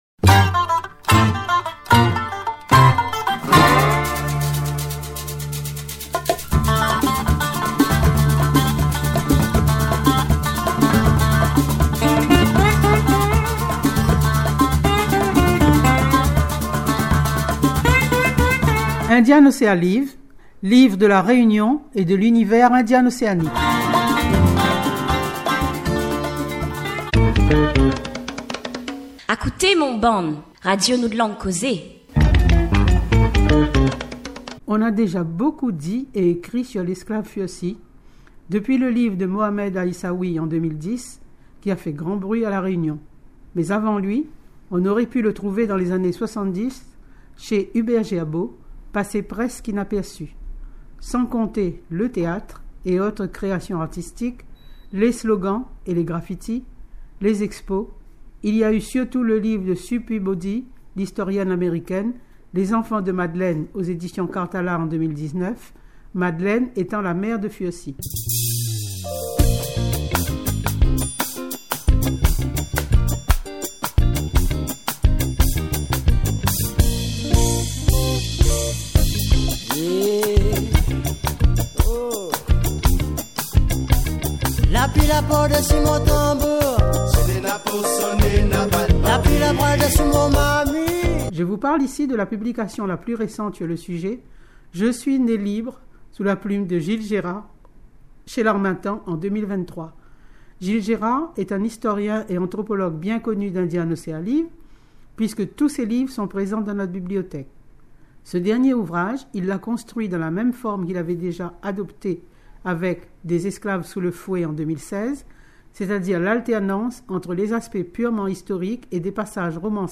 Chronique radio sur "Je suis né libre"